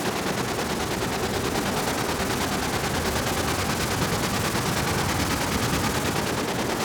STK_MovingNoiseB-140_03.wav